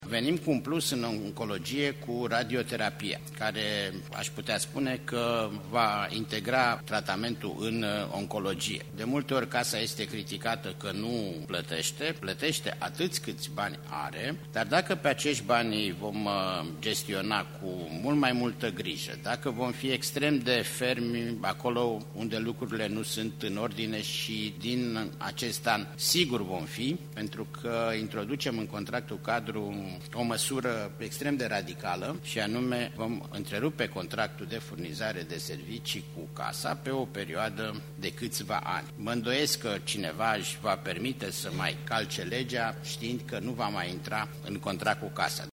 Aceşti bani vor ramane în sistemul sanitar pentru medicamente, a declarat preşedintele Casei Naţionale de Asigurări de Sănătate, Vasile Ciurchea, prezent la „Health Forum – Finanţarea Sistemului de Sănătate 2015”.